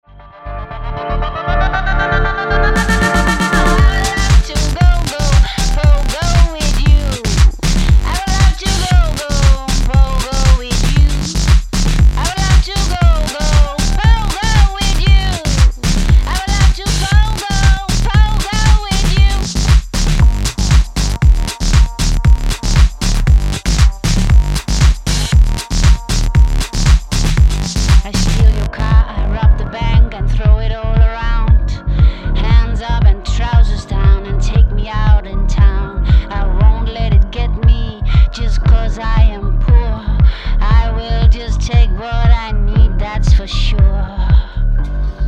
• Качество: 256, Stereo
женский голос
Electronic
Bass House
качающие
Крутой клубнячок